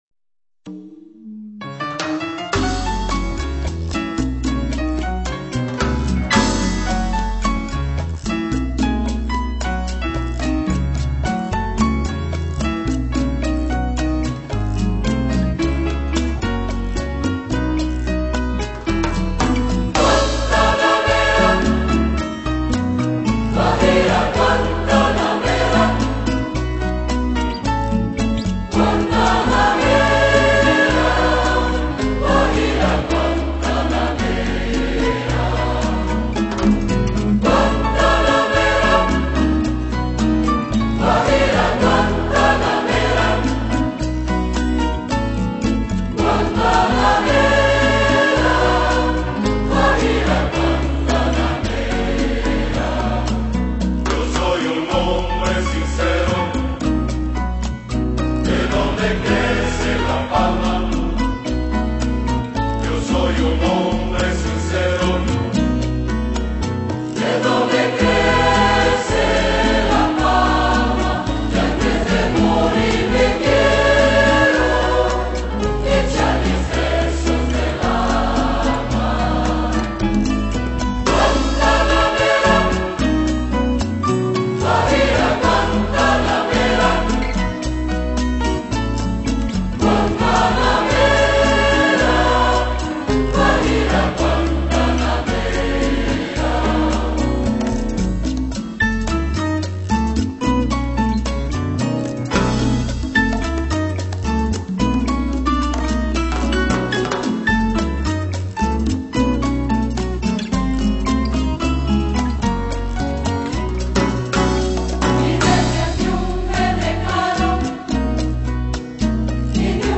Pianistas